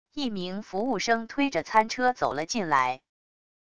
一名服务生推着餐车走了进来wav音频生成系统WAV Audio Player